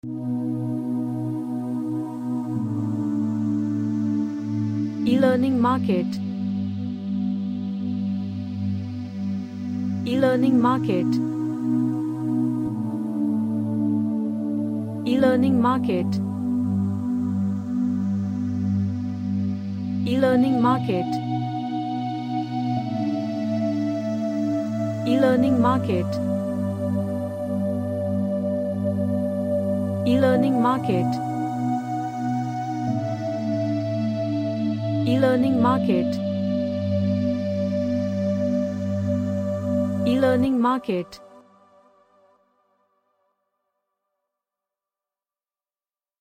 A relaxing ambient track.
Relaxation / Meditation